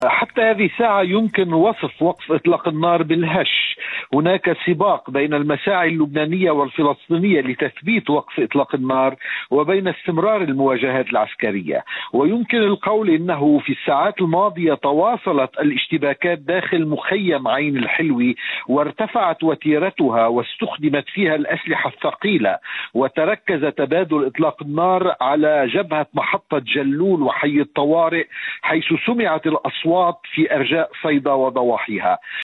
نشرة اخبار الصباح 1/8/2023